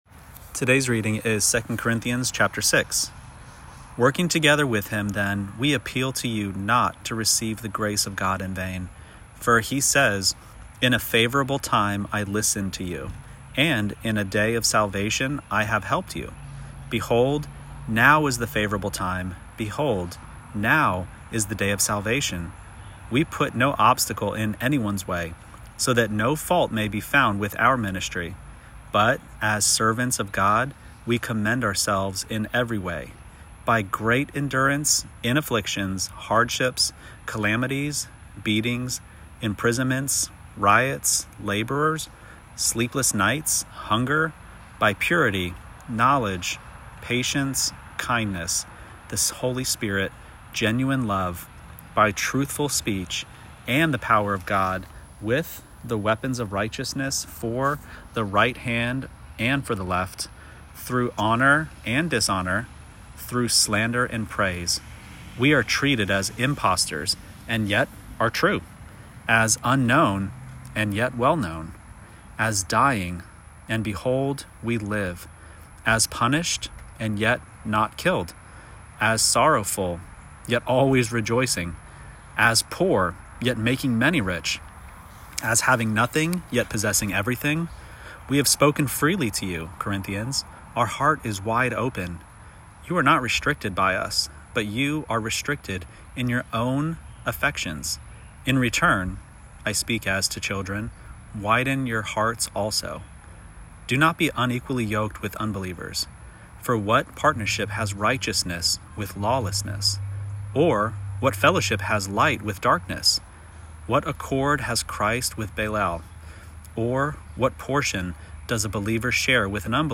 Daily Bible Reading